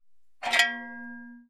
Metal_53.wav